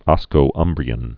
(ŏskō-ŭmbrē-ən)